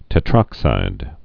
(tĕ-trŏksīd)